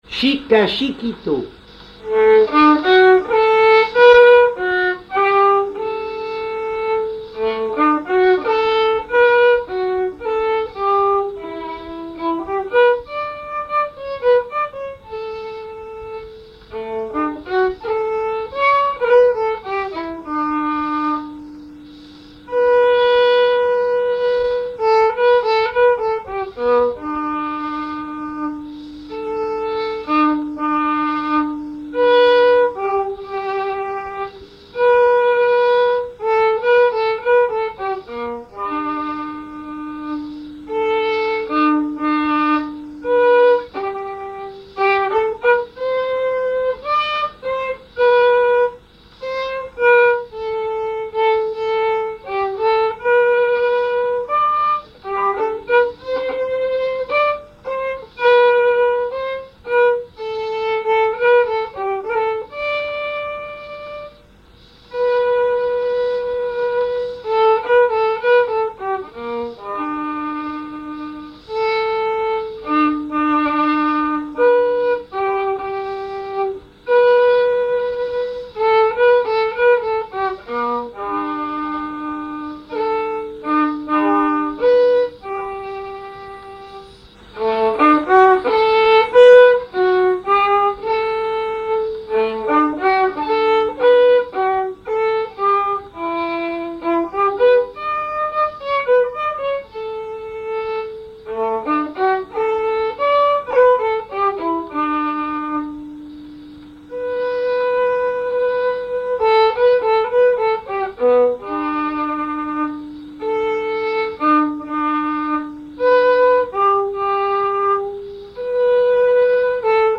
Genre brève
recherche de répertoire de violon
Pièce musicale inédite